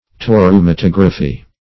Search Result for " toreumatography" : The Collaborative International Dictionary of English v.0.48: Toreumatography \To"reu`ma*tog"ra*phy\, n. [Gr.